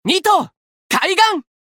刀剑乱舞_Horikawa-doubleattackcallout.mp3